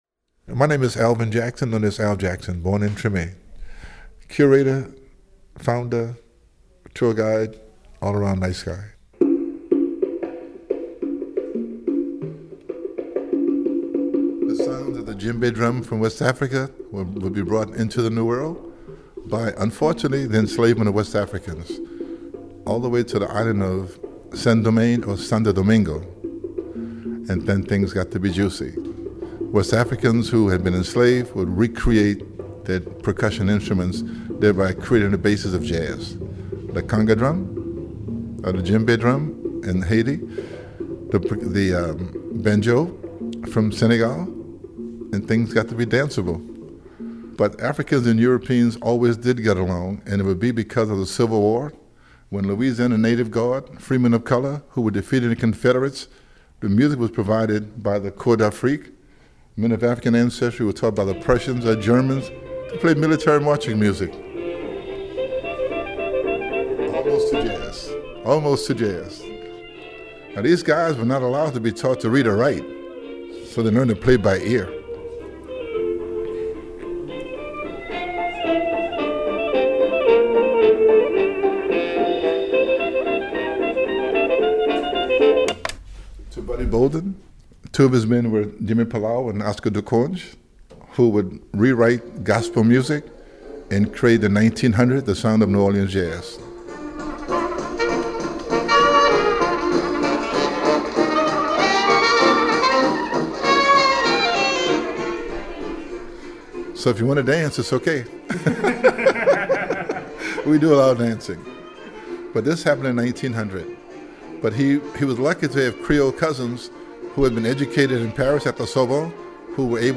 This interview has been edited for length and clarity.